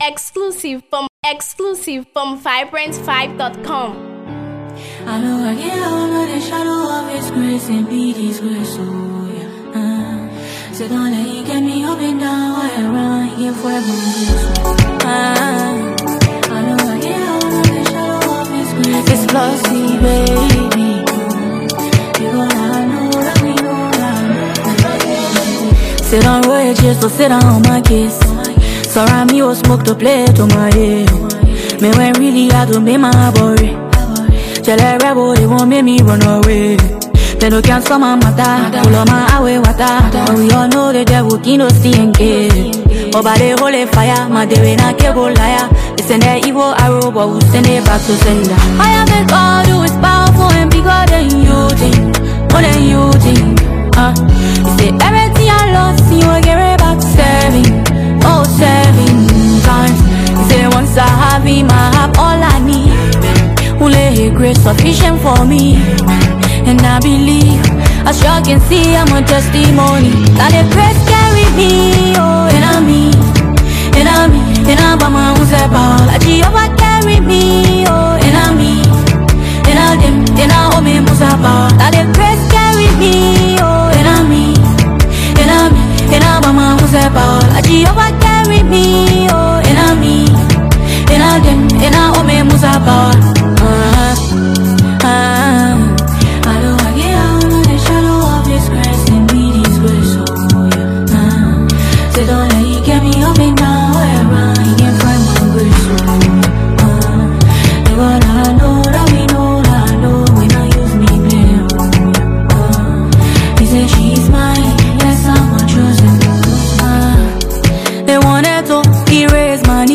Afro-Gospel song
contemporary gospel music